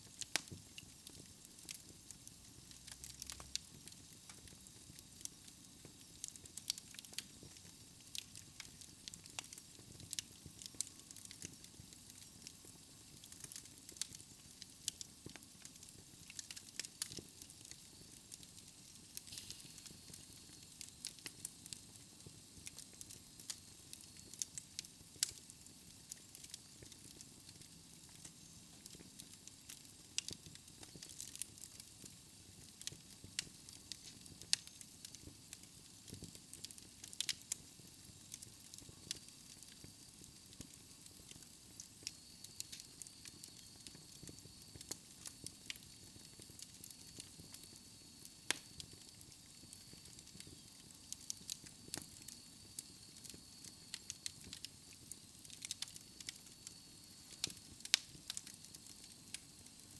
Feu-de-cheminée.mp3